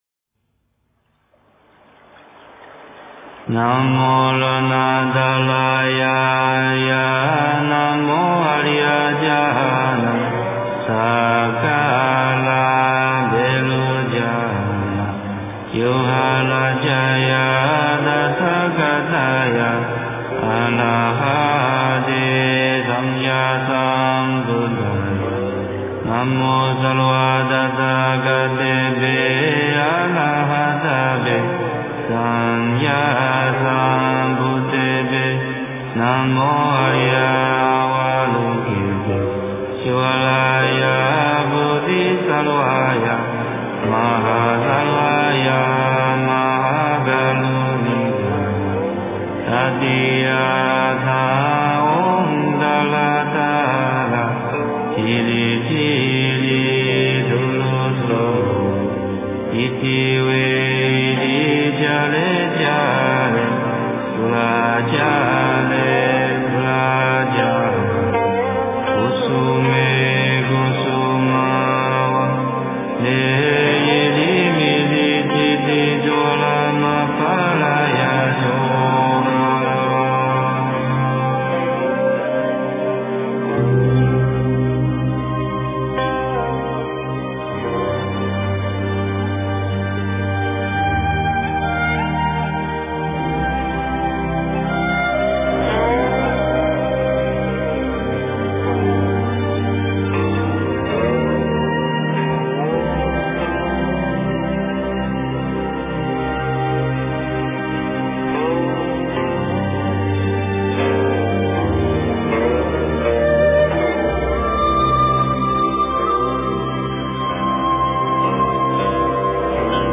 大悲咒-梵音 诵经 大悲咒-梵音--佚名 点我： 标签: 佛音 诵经 佛教音乐 返回列表 上一篇： 六字真言 下一篇： 大悲咒 相关文章 大白伞盖佛母咒--贵族乐团 大白伞盖佛母咒--贵族乐团...